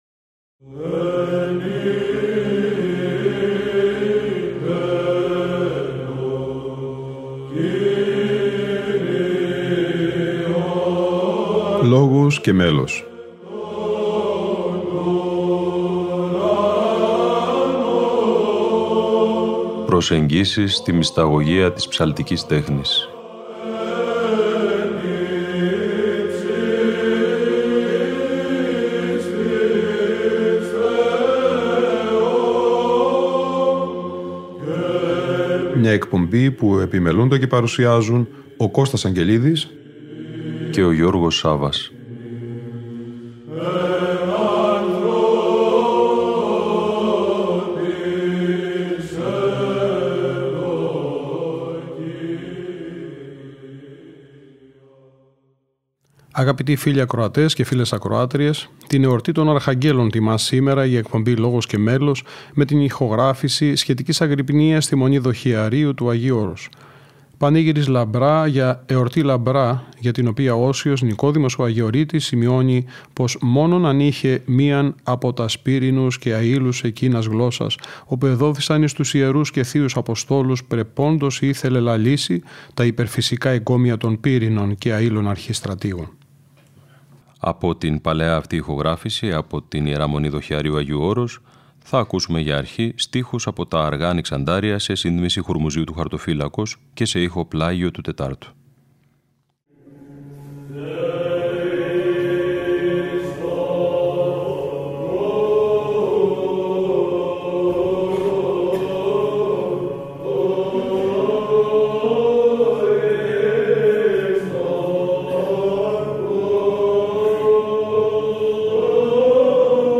Αγρυπνία Αρχαγγέλων στην Ι.Μ. Δοχειαρίου (Α΄)